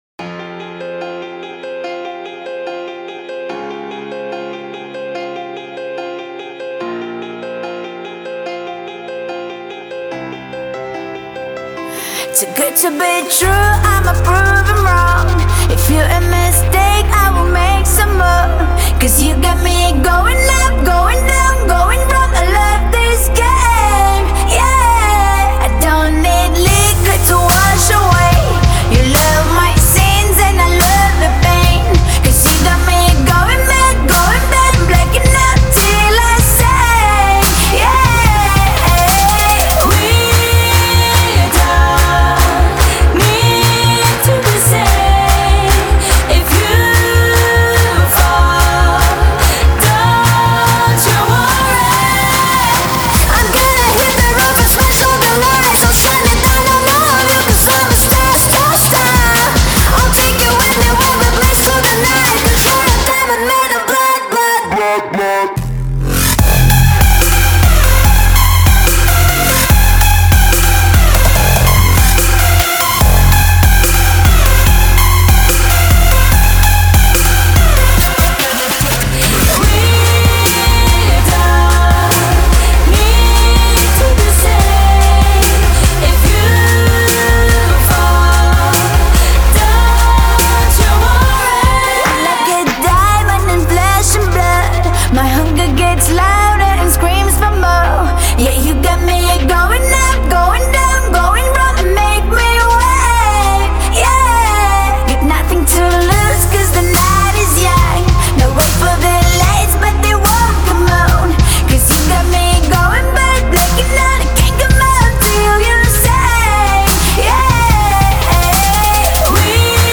• Жанр: Electronic, EDM, House